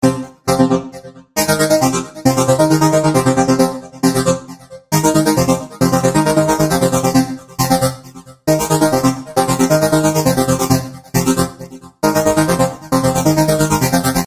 爵士乐
描述：Ason 4合成器循环
Tag: 135 bpm Techno Loops Synth Loops 2.39 MB wav Key : Unknown